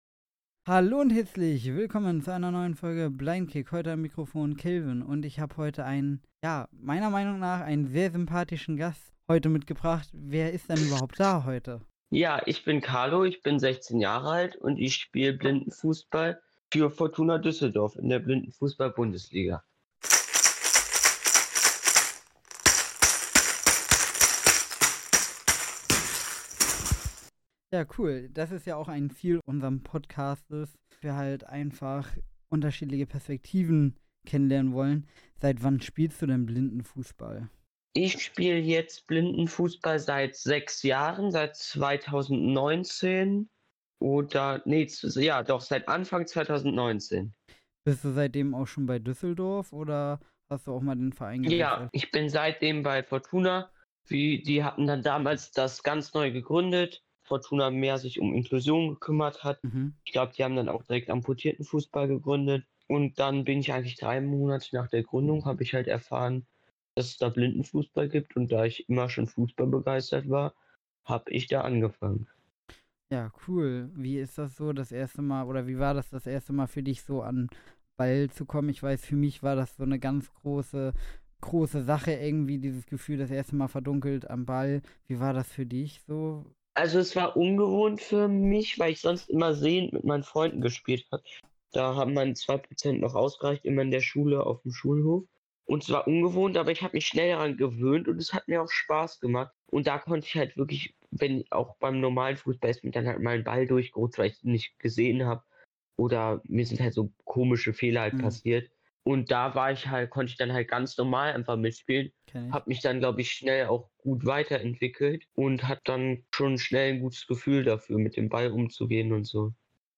Thematisiert werden die ersten Herausforderungen, persönliche Erfahrungen und die Motivation, am Ball zu bleiben. Ein Gespräch über Mut, Zusammenhalt und Teamgeist.